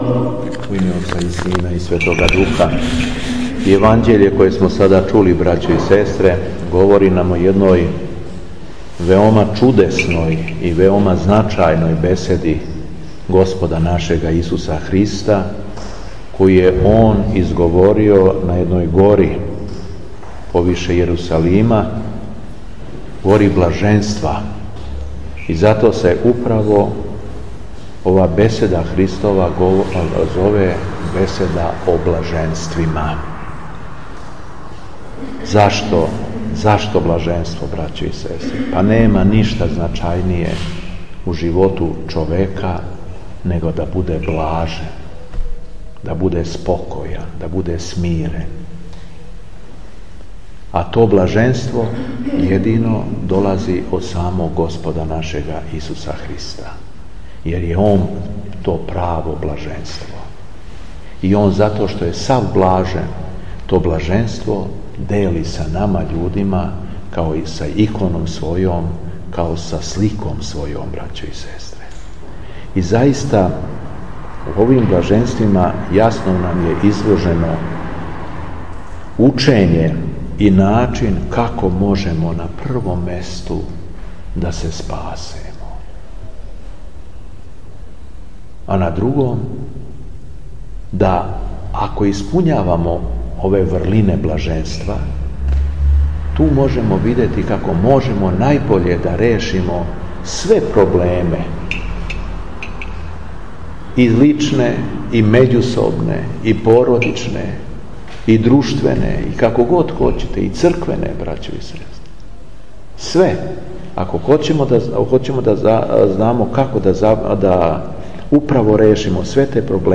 Беседа Епископа шумадијског Г. Јована